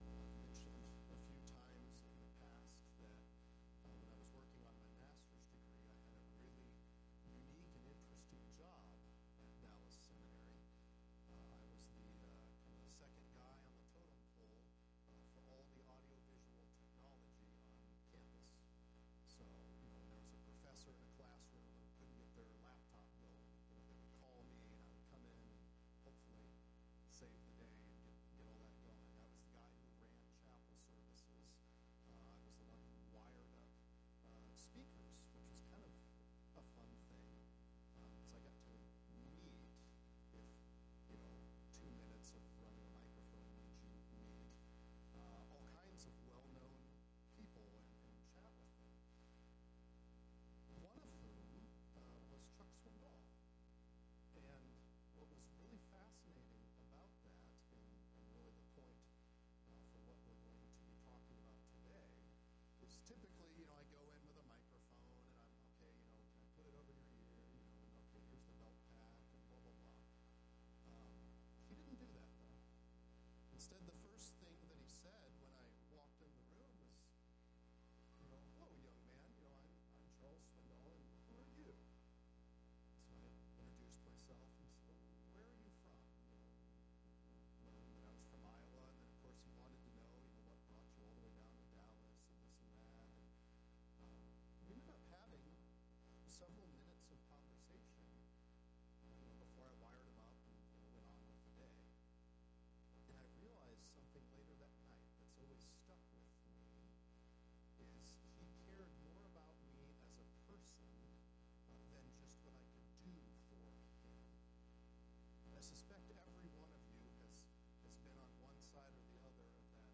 Mark 8:11-21 Focus on the King – Sermons